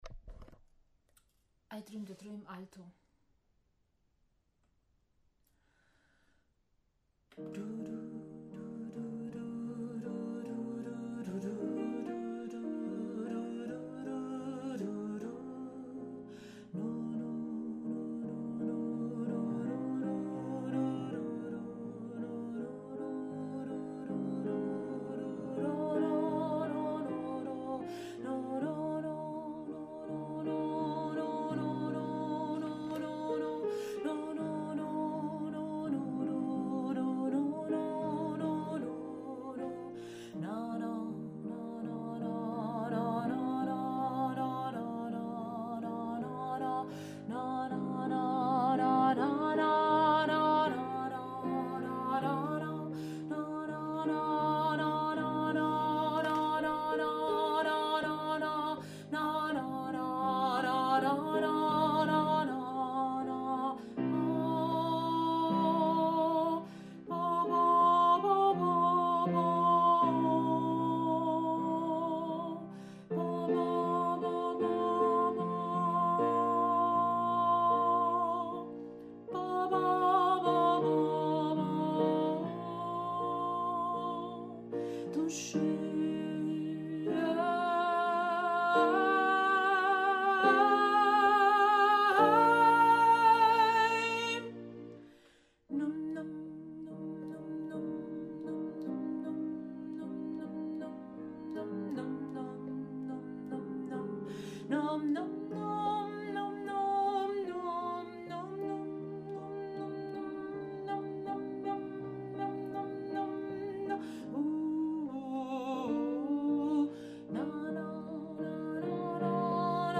I dreamed a dream – Alto